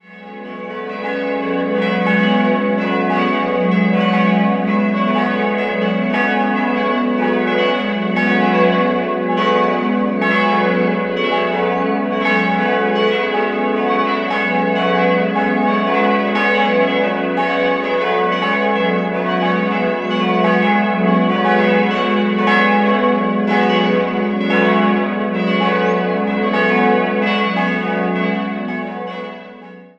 4-stimmiges TeDeum-Geläut: fis'-gis'-h'-cis'' Die Glocken wurden 1956 von Friedrich Wilhelm Schilling in Heidelberg gegossen, die große entstand 1999 in Karlsruhe.